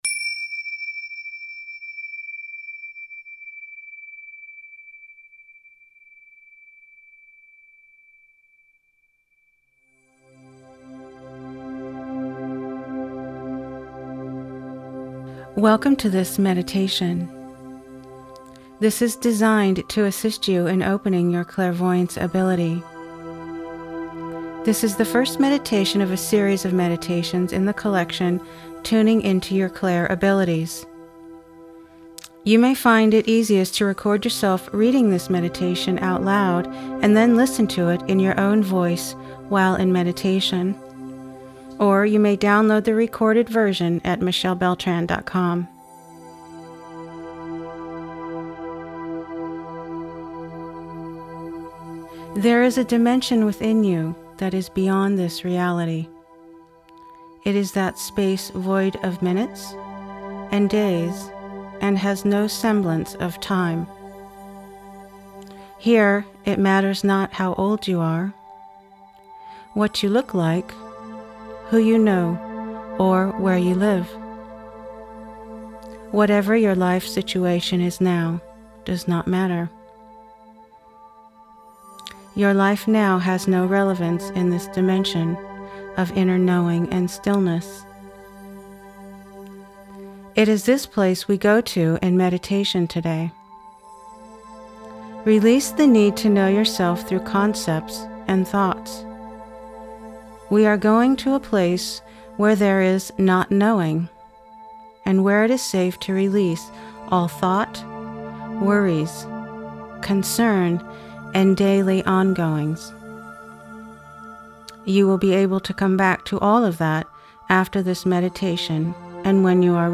clairvoyance-meditation